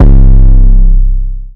808 11 [ jetson ].wav